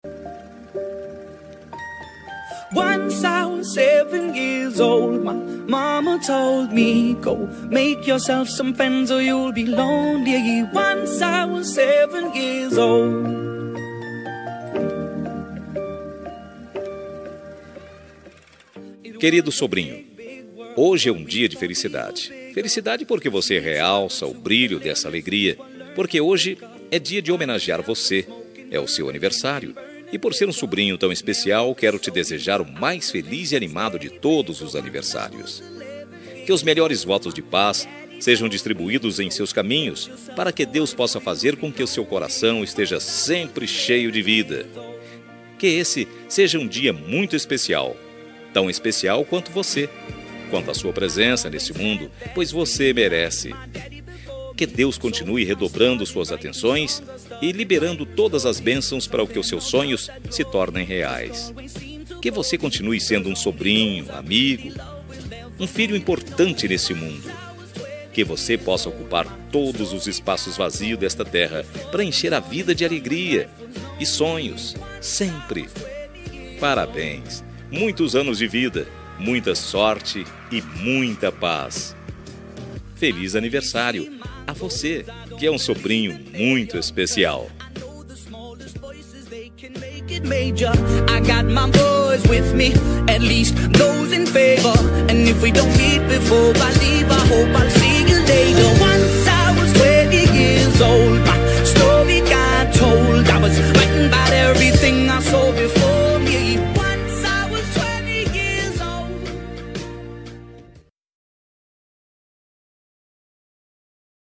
Aniversário de Sobrinho – Voz Masculina – Cód: 42620